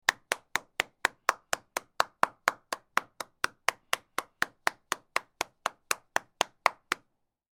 Single Person Clapping